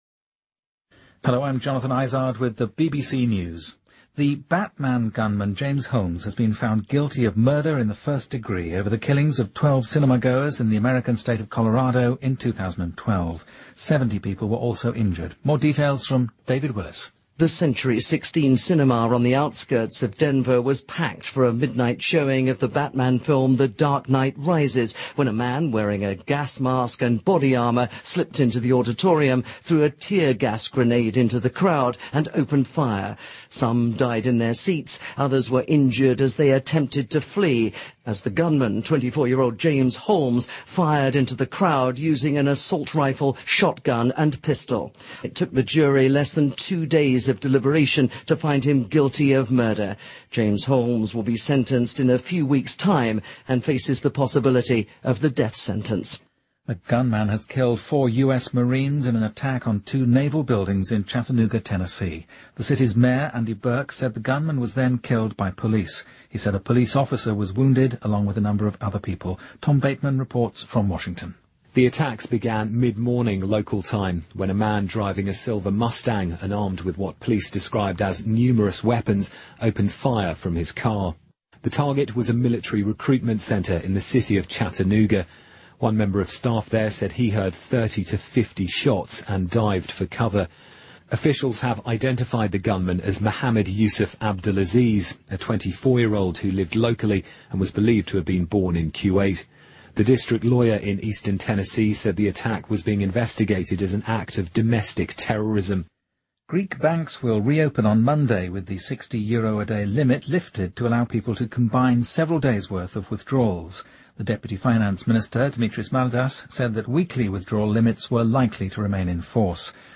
日期:2015-07-18来源:BBC新闻听力 编辑:给力英语BBC频道